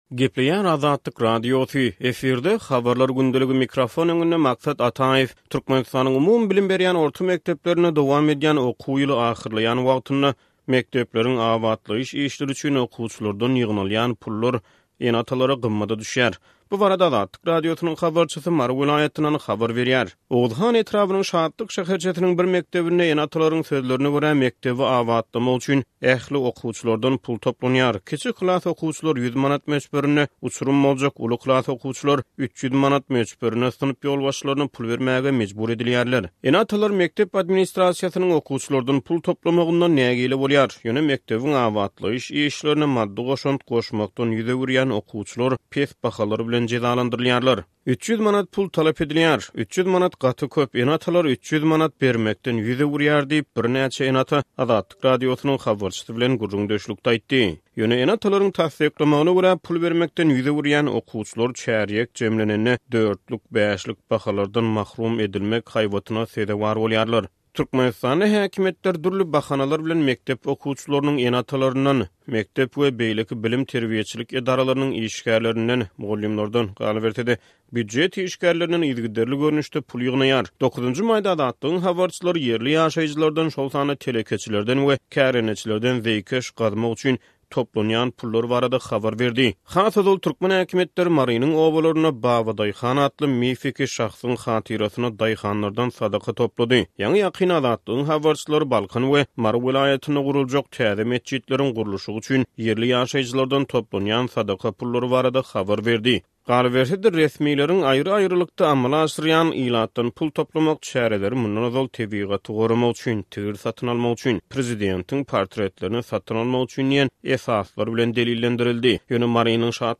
Türkmenistanyň umumybilim berýän orta mekdeplerinde dowam edýän okuw ýyly ahyrlaýan wagtynda, mekdepleriň abatlaýyş işleri üçin okuwçylardan ýygnalýan pullar ene-atalara gymmada düşýär. Bu barada Azatlyk Radiosynyň habarçysy Mary welaýatyndan habar berýär.